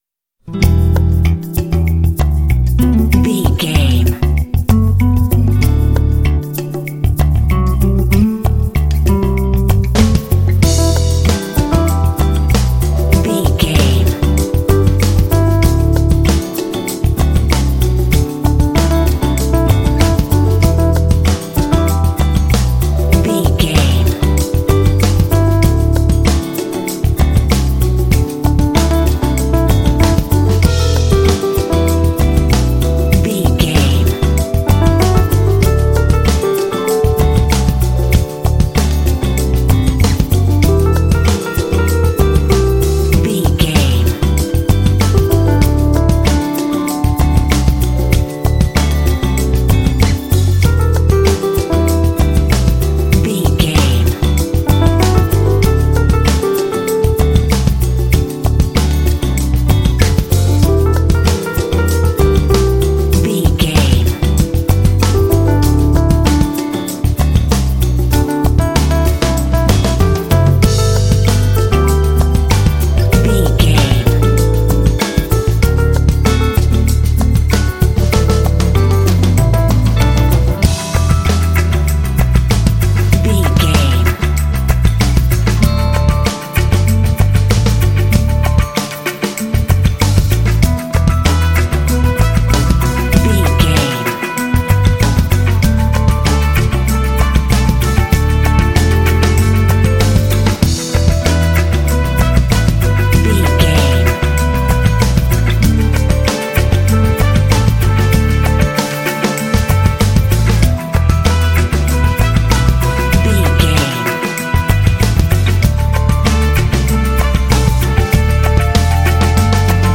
Aeolian/Minor
sultry
groovy
bass guitar
acoustic guitar
drums
electric piano
strings
brass
Funk